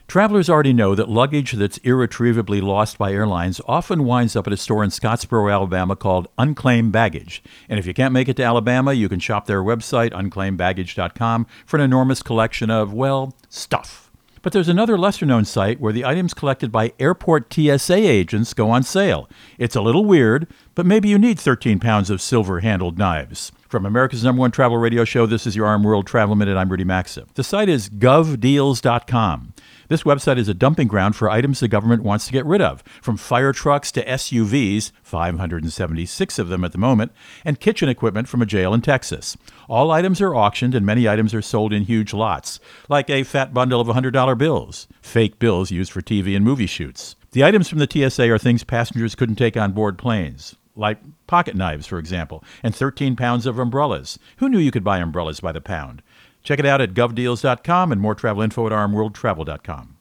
Co-Host Rudy Maxa | TSA Bargain Shopping